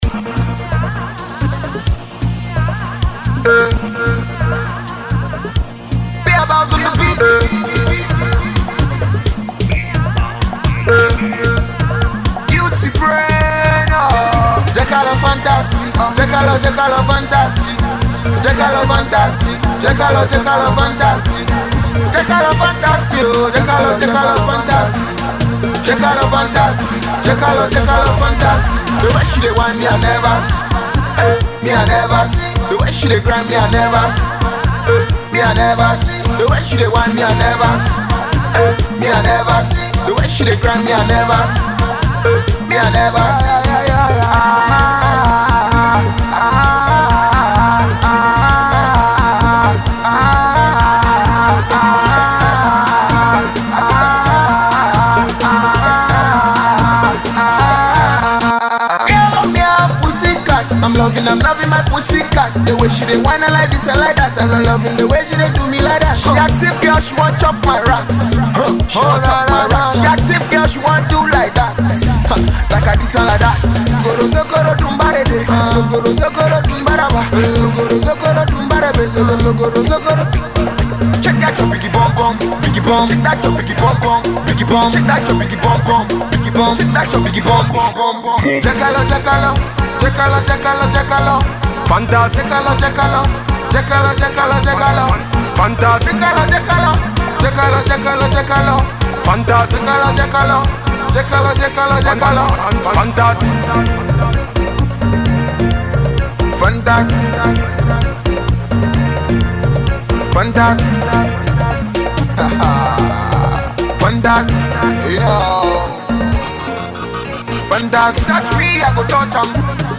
the single doesn’t sound good